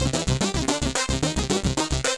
Index of /musicradar/8-bit-bonanza-samples/FM Arp Loops
CS_FMArp B_110-C.wav